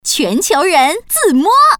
Index of /mahjong_xianlai/update/12943/res/sfx/common_woman/